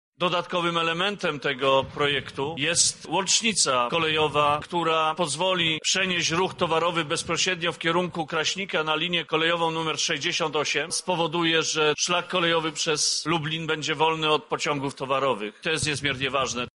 Ta inwestycja to jeden z kluczowych projektów Krajowego Programu Kolejowego – mówi minister infrastruktury Andrzej Adamczyk.